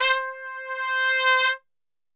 C 3 TRPSWL.wav